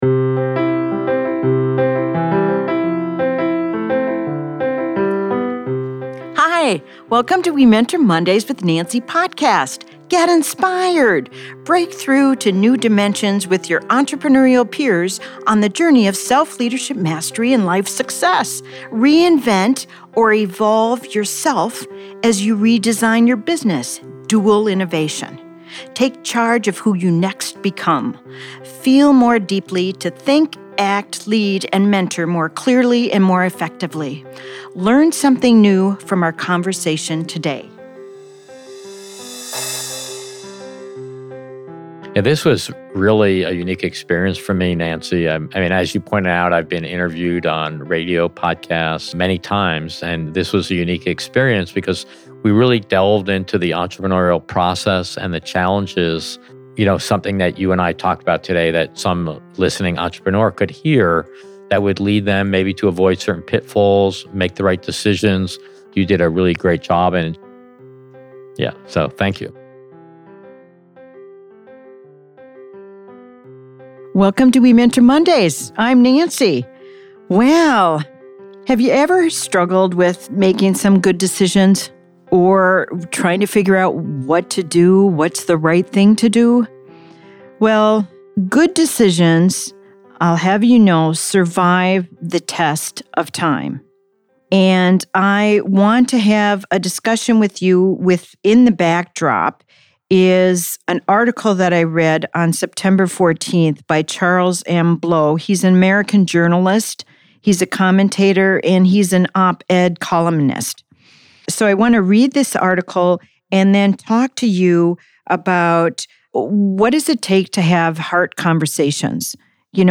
I read this article aloud and then talk about it because his words validate why our future hangs in the balance of what we do right now. He begins by wondering how major world tragedies and horrors were allowed to unfold.